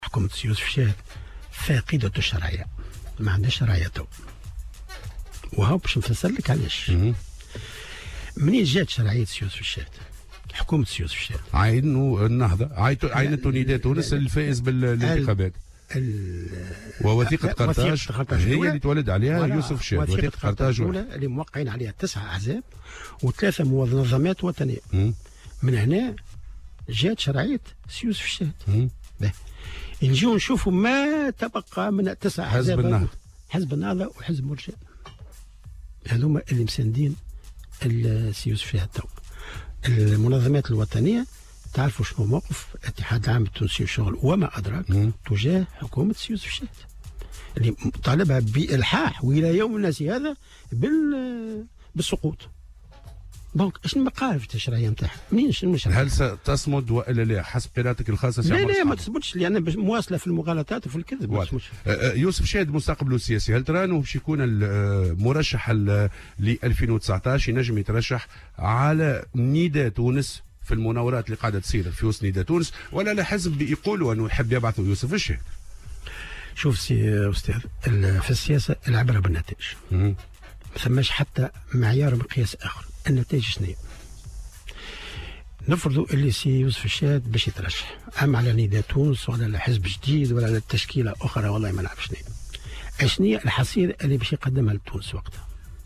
وأضاف في مداخلة له اليوم الأربعاء في برنامج "صباح الورد" على "الجوهرة أف أم" أن هذه الحكومة "فاقدة للشرعية"، موضحا أنها انبثقت عن وثيقة قرطاج الأولى، التي وقعت عليها 9 أحزاب و3 منظمات وطنية، لكنها أصبحت الآن دون سند، بعد أن سحبت أغلبية الأحزاب البساط منها، باستثناء حزبي النهضة والمبادرة.